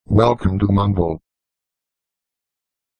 Mumble mluví.
welcome_to_mumble.mp3